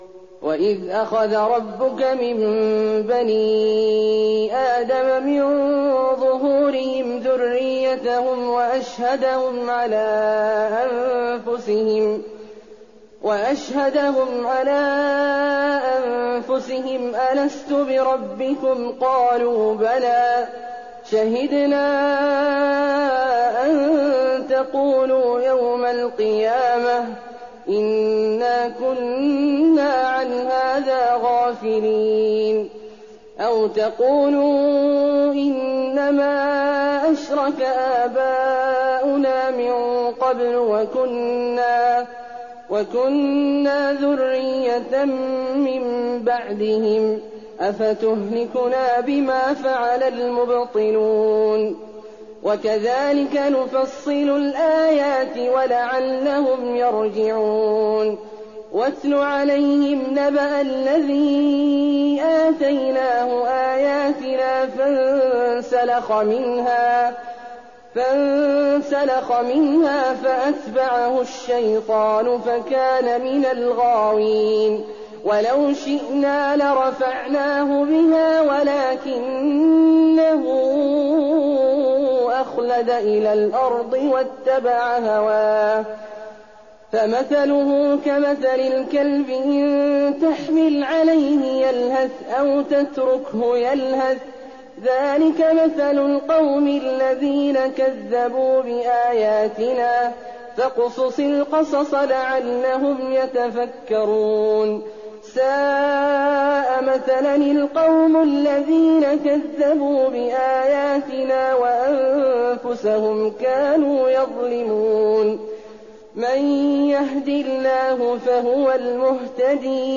تراويح الليلة التاسعة رمضان 1419هـ من سورتي الأعراف (172-206) و الأنفال (1-40) Taraweeh 9th night Ramadan 1419H from Surah Al-A’raf and Al-Anfal > تراويح الحرم النبوي عام 1419 🕌 > التراويح - تلاوات الحرمين